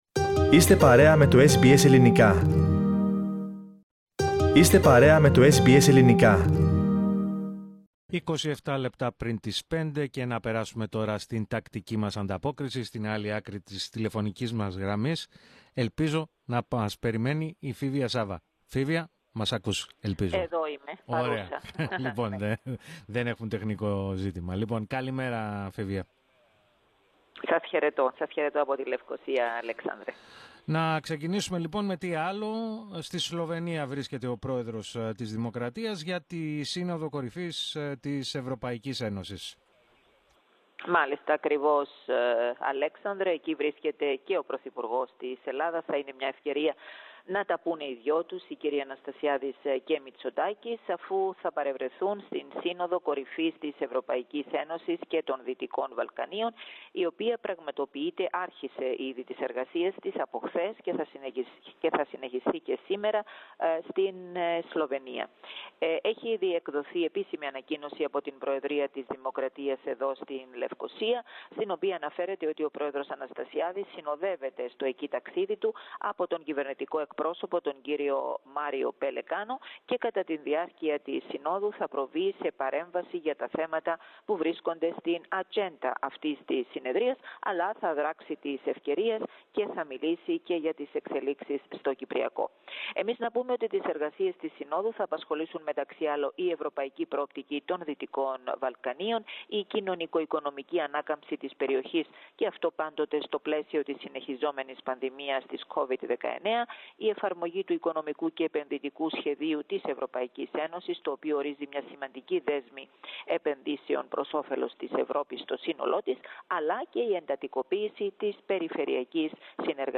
ανταπόκριση